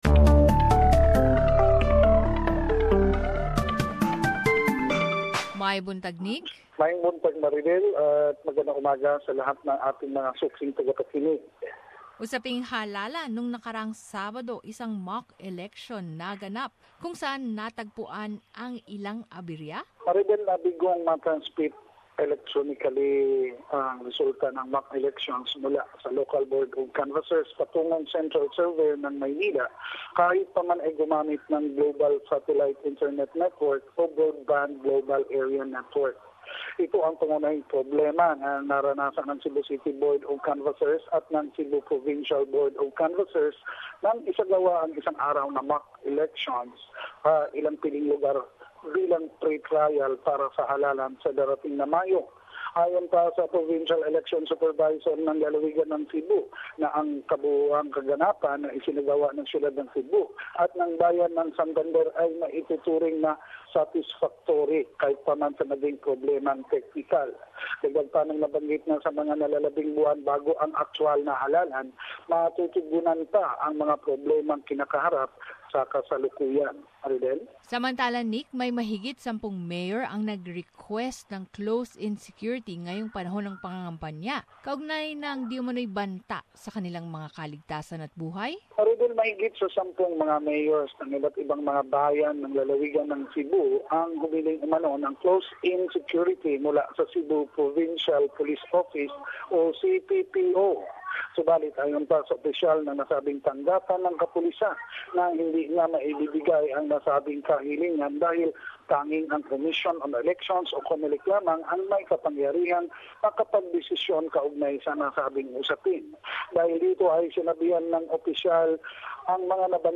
Balitang Bisayas. Summary of latest relevant news